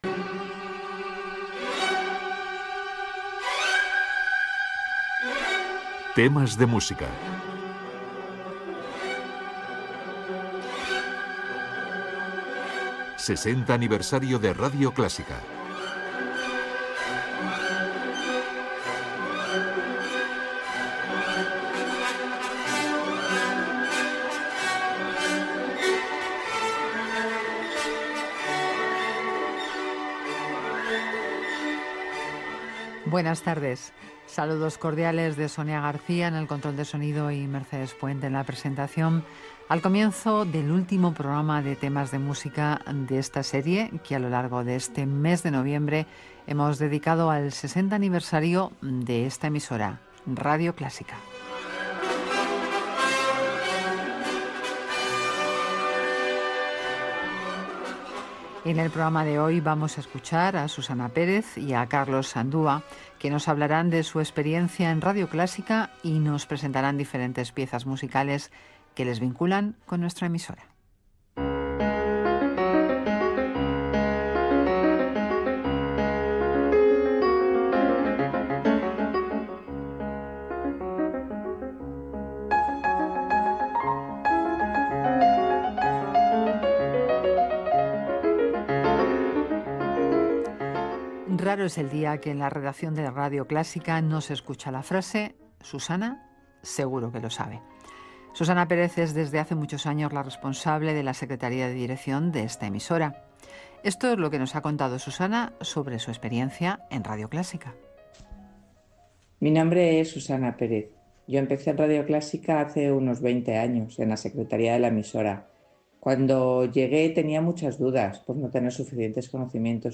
Careta del programa, espai dedicat als 60 anys de Radio Clásica, la dècada de 2015 a 2025 (segona part).
Gènere radiofònic Musical